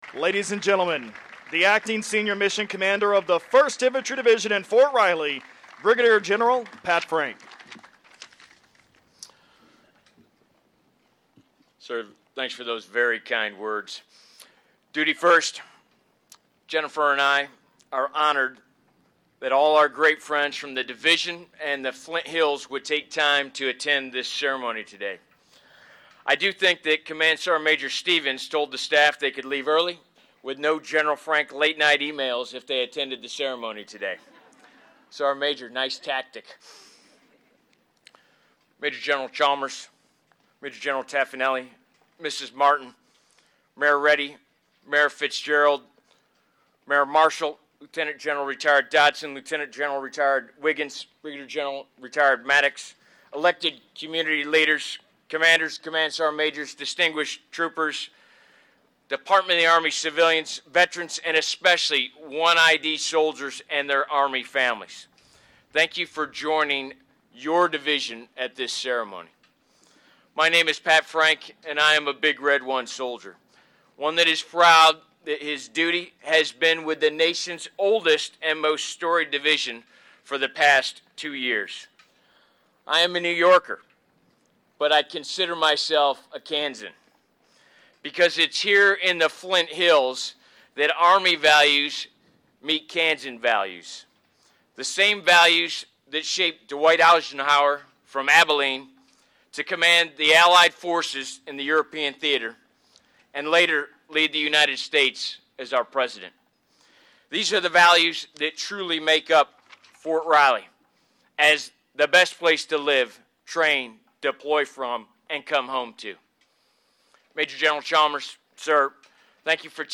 Departing Brig. Gen. Patrick D. Frank addresses the crowd Tuesday in front of the Big Red One headquarters.
In his remarks to the large crowd, with representatives of the post’s many divisions behind him, Frank said the Big Red One is a substantial part of his identity.